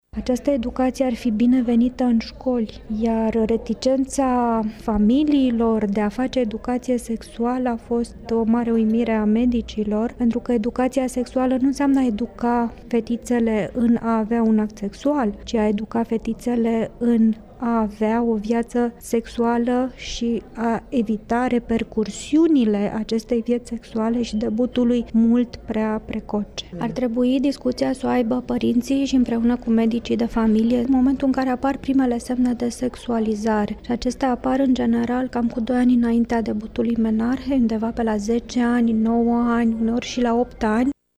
Conferenţiarul universitar doctor